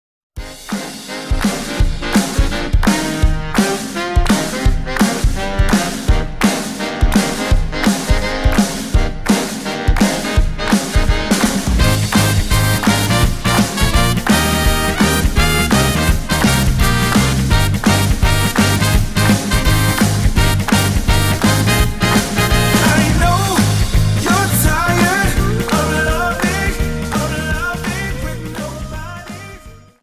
Dance: Jive 43